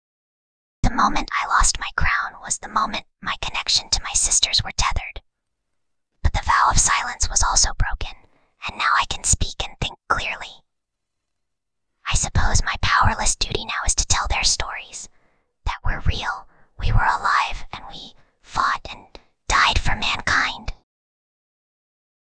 Whispering_Girl_39.mp3